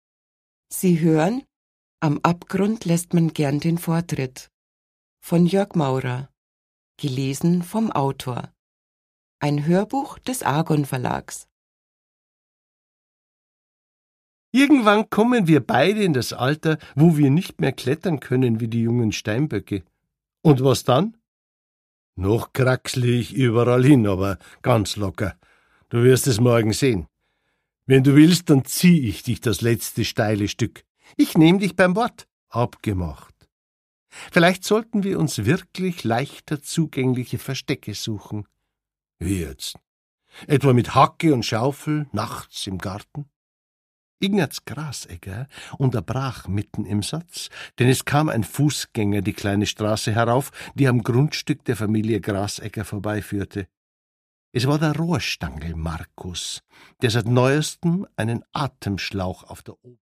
Produkttyp: Hörbuch-Download
Gelesen von: Jörg Maurer